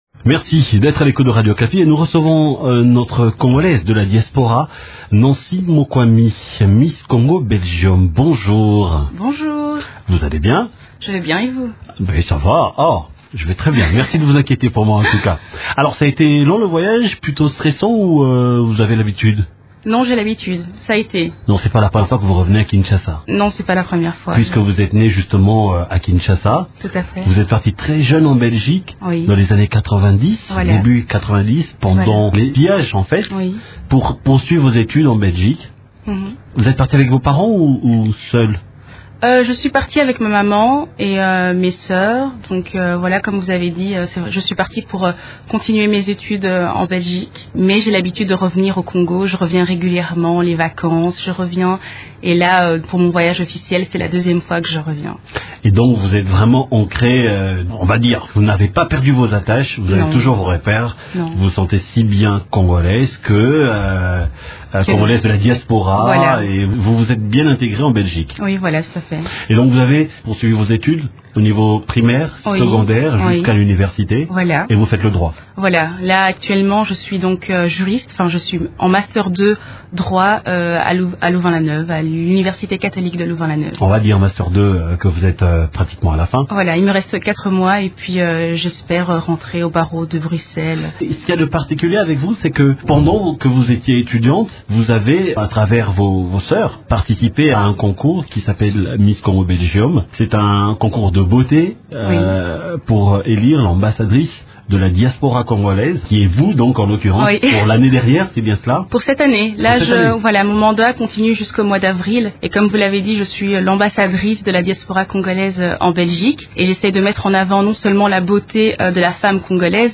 Ici dans le studio de Radio Okapi en février 2014.